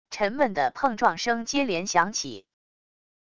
沉闷的碰撞声接连响起wav音频